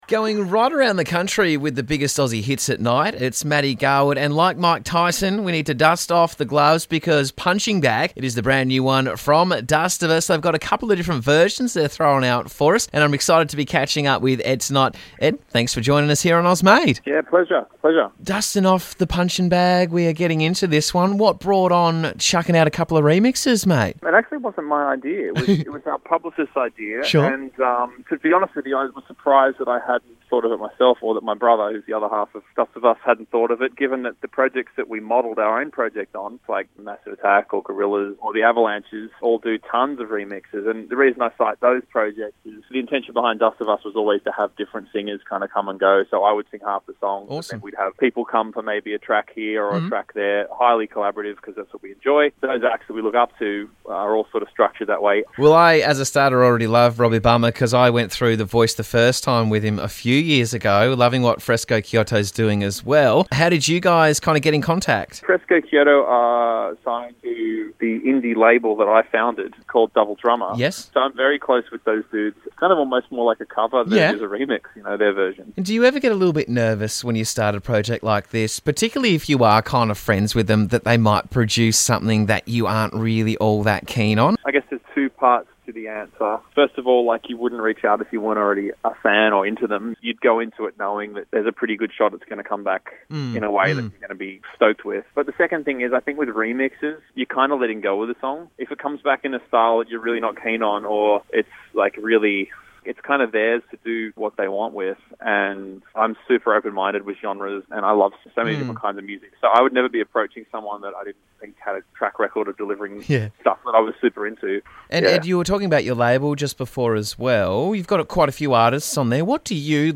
vocalist
the additional dynamic bringing added drama and weight.